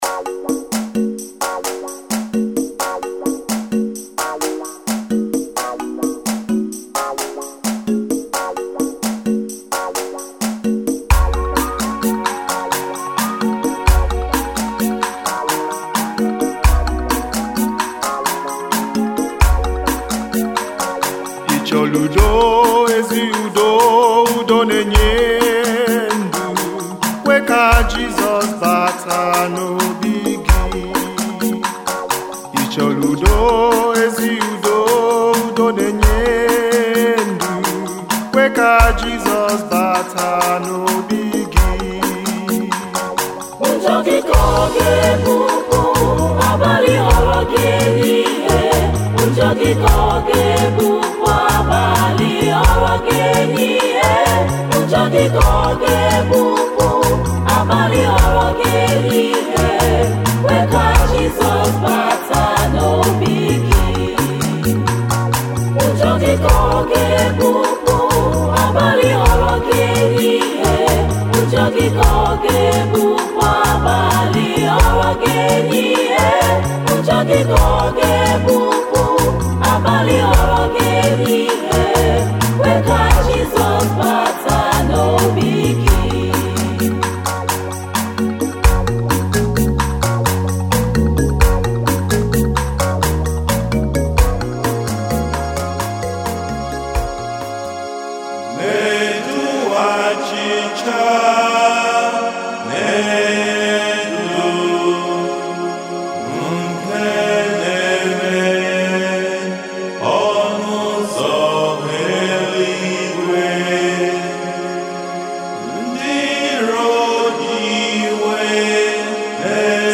This audio is a complete rites of the adoration of our Lord Jesus Christ in the Blessed Sacrament. The Lord Jesus gave us his body and blood as the Sacrament of his love.